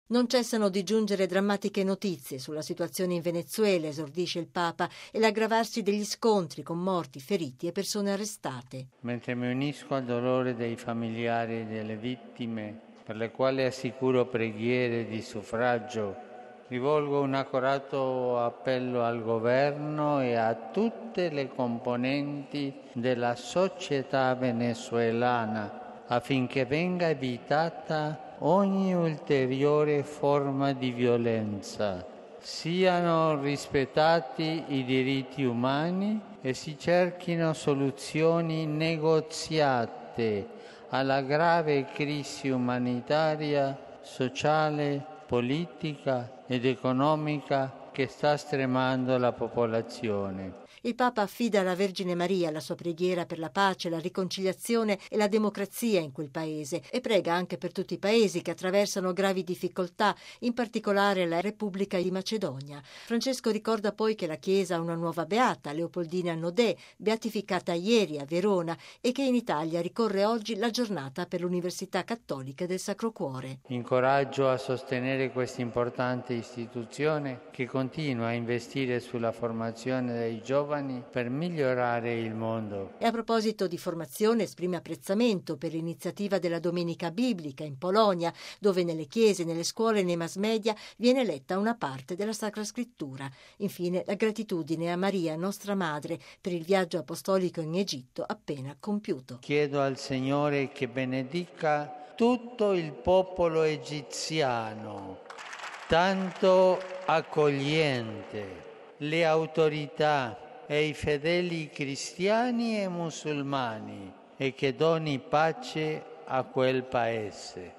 Le notizie drammatiche che giungono dal mondo e in particolare dal Venezuela, al centro del pensiero di Papa Francesco oggi al Regina Coeli recitato direttamente da Piazza San Pietro al termine dell’incontro con l’Azione Cattolica Italiana.